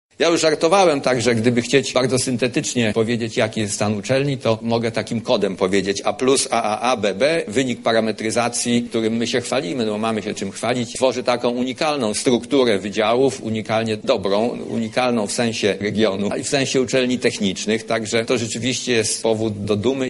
Politechnika Lubelska uroczyście zainaugurowała 65 rok akademicki działalności.
O tym jakie wskaźniki w ostatnim roku osiągnęła Politechnika Lubelska mówi jej rektor, prof. dr hab. inż. Piotr Kacejko.